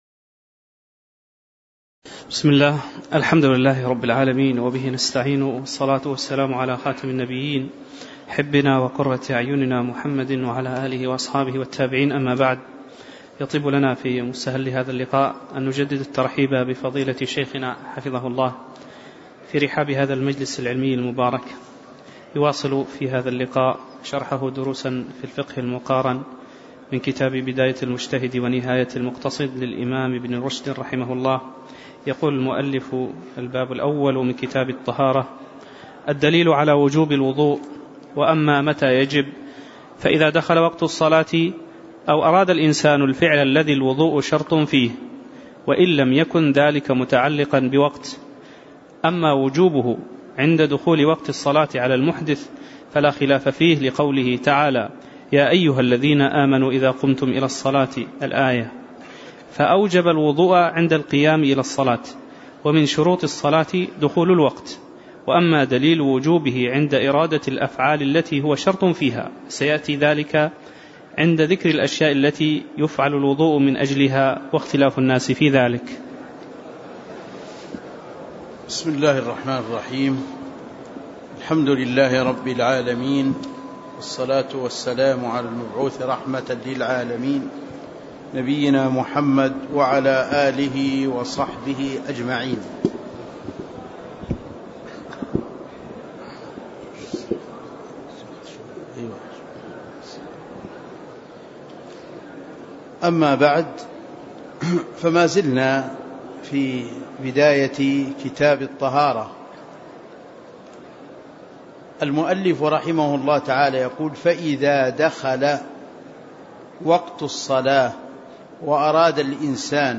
تاريخ النشر ١٧ جمادى الأولى ١٤٣٩ هـ المكان: المسجد النبوي الشيخ